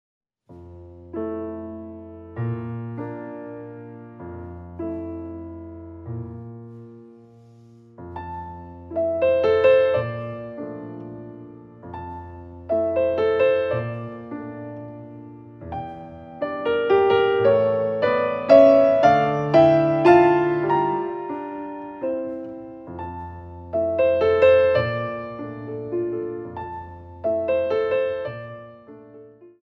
Ballet class music for Intermedite Level
Beautifully recorded on a Steinway and Sons Grand Piano